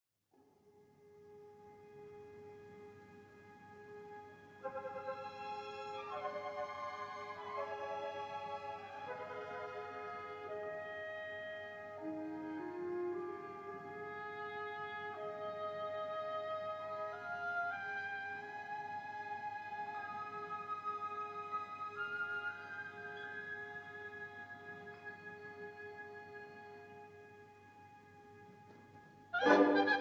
This is a 1958 stereo recording